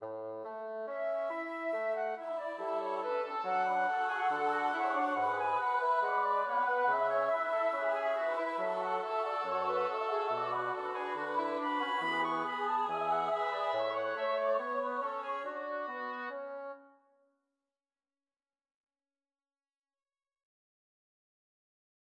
J. S. 바흐칸타타 BWV 9, ''구원은 우리에게서 오네''에 수록된 듀엣 아리아 "Herr, du siehst statt guter Werke"는 플루트와 오보에 사이, 그리고 소프라노와 알토 성부 사이에서 이중 카논을 특징으로 한다.
바흐, 칸타타 BWV 9의 듀엣 아리아 "Herr, du siehst statt guter Werke"의 발췌